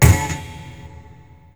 Hit Odd.wav